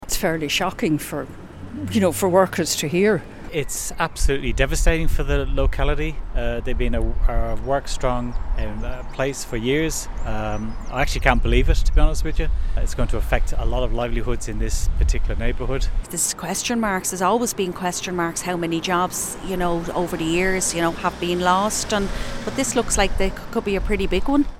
Speaking to Kfm, locals said job cuts would devastate the community.